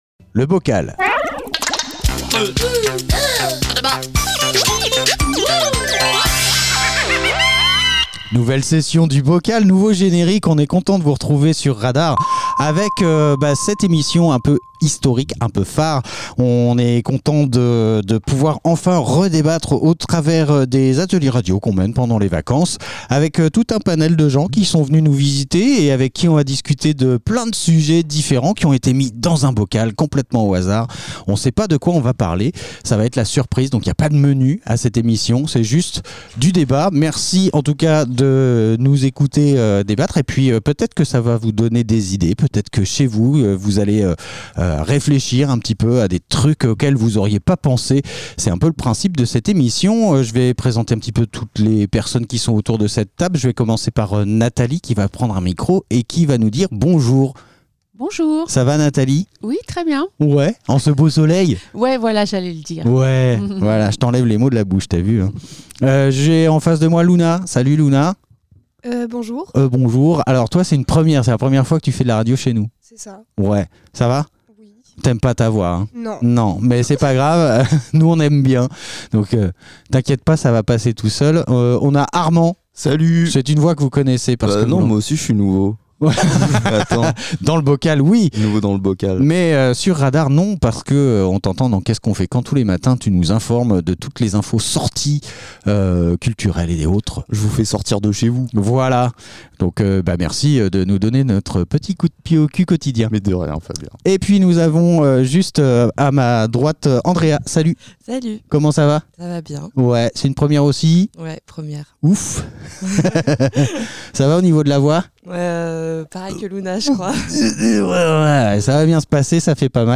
Une poignée d'invités propose des sujets de débats à bulletin secret.
Le sujet, une fois dévoilé, donne lieu à des conversations parfois profondes, parfois légères, toujours dans la bonne humeur !